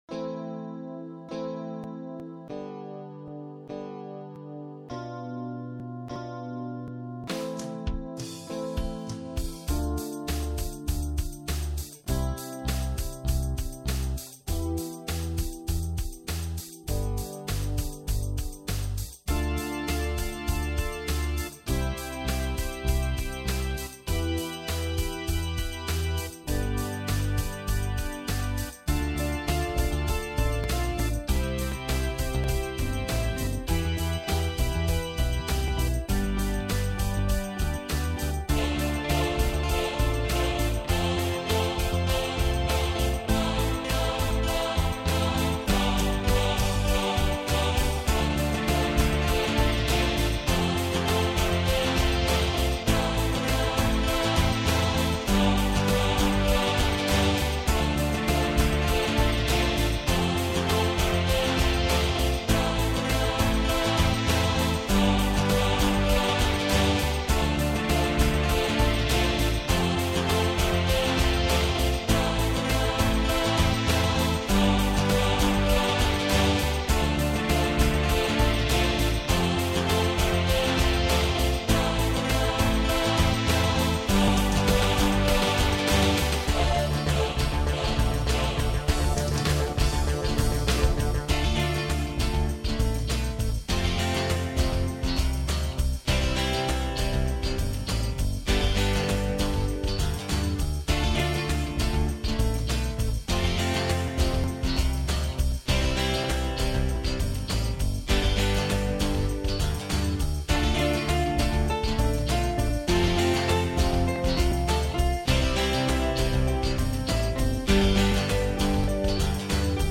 3,9 MB 1997 Pop